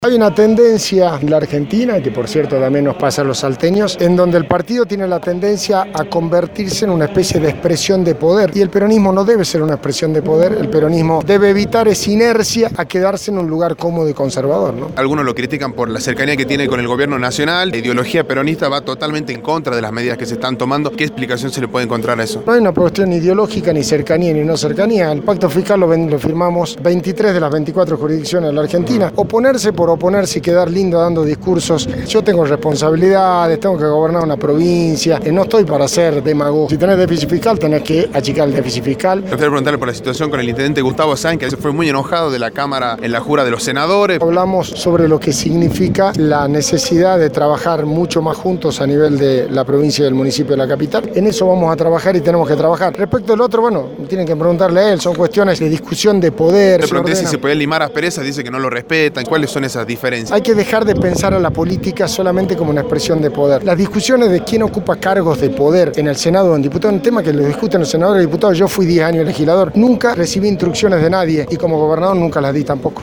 «Hay una tendencia en la Argentina, que por cierto también nos pasa a los salteños, en donde el partido tiene la tendencia a convertirse en una especie de expresión de poder y el peronismo no debe ser una expresión de poder. El peronismo debe evitar esa inercia de quedarse en  un lugar cómodo y conservador», manifestó Urtubey a Radio Dinamo.